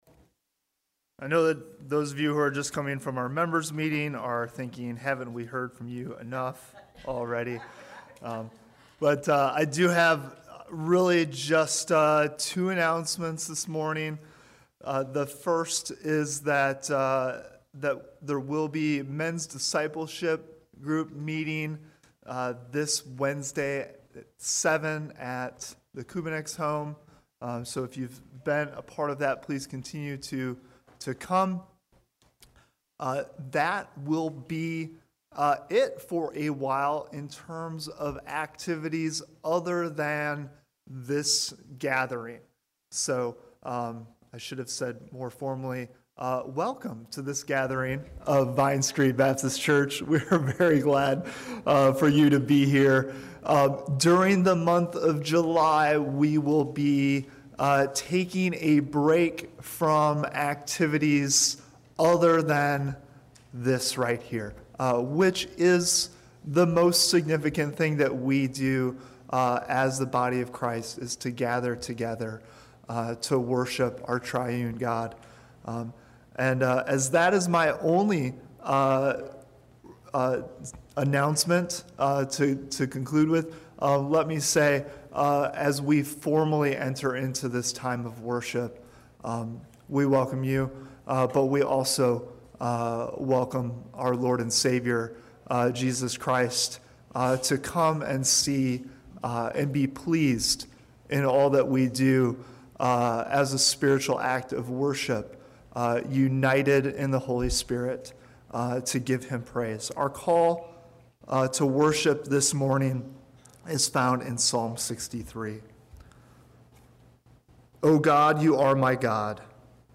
June 26 Worship Audio – Full Service